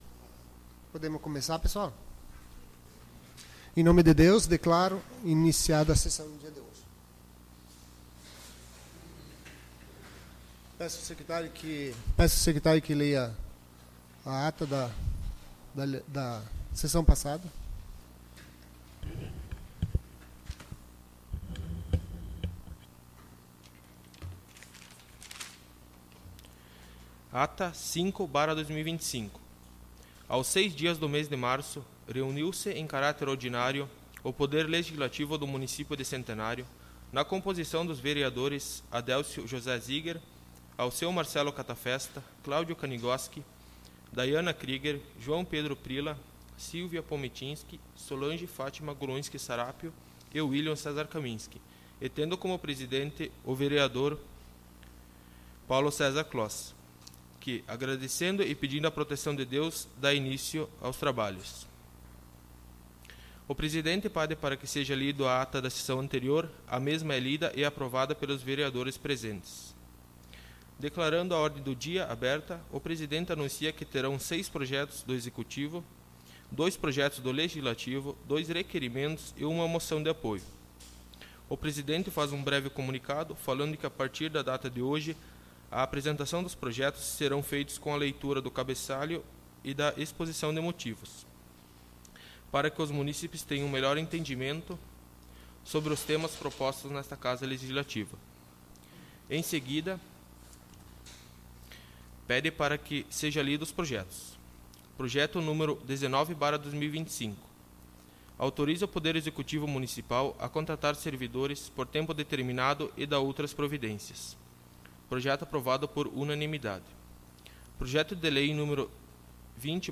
Sessão Ordinária 17/03/2025